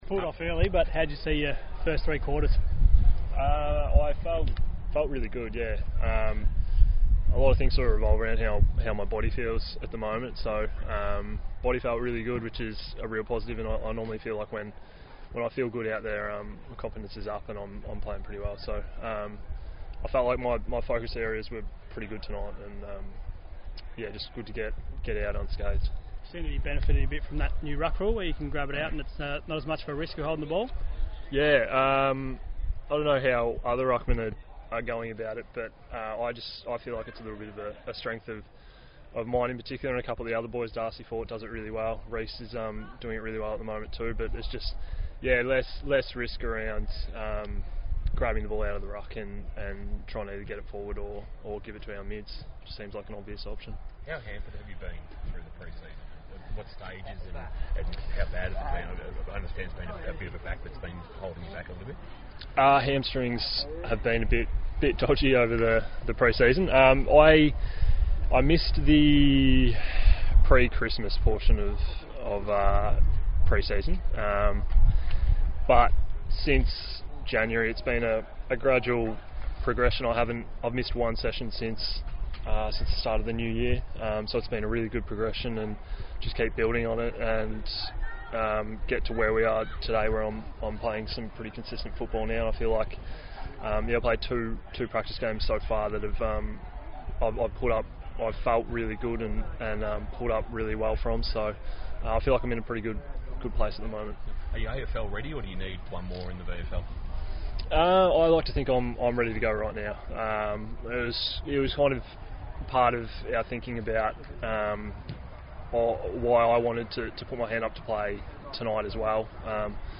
spoke to the media following the Cats' VFL practice match against Werribee.